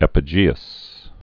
(ĕpə-jēəs)